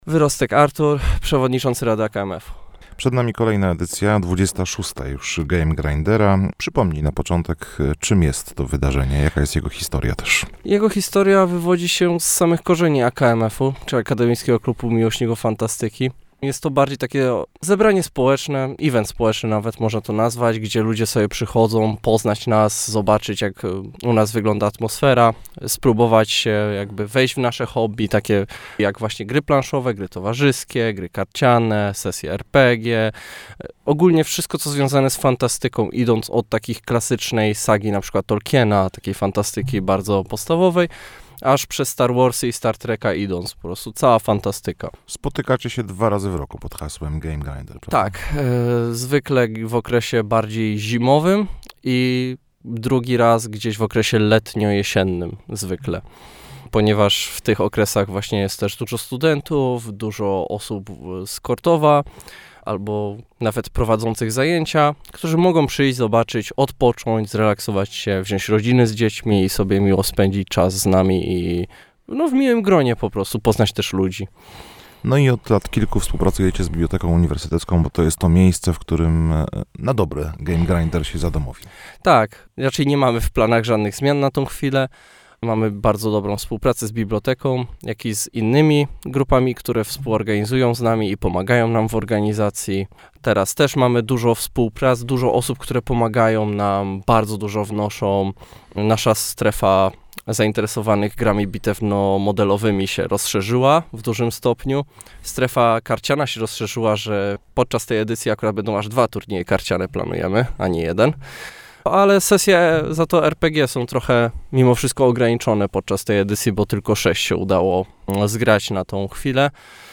– mówił w naszym studiu